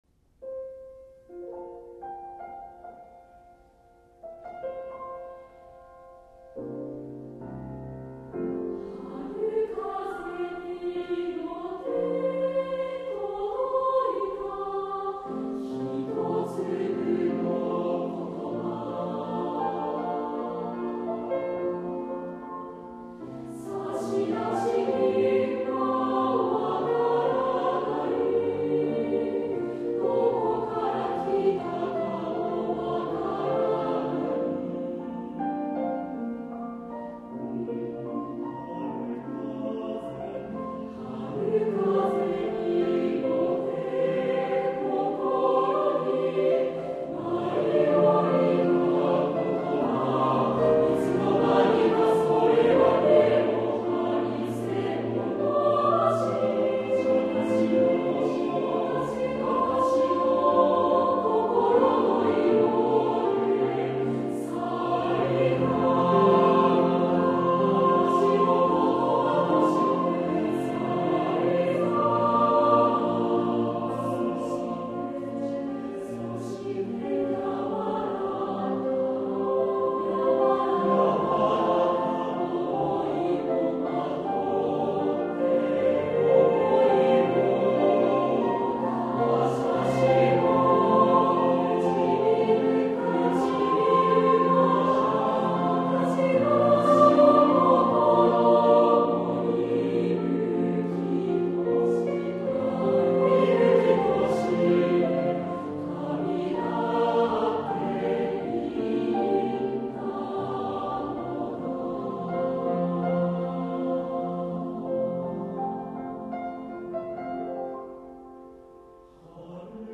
for Mixed Chrus, and Piano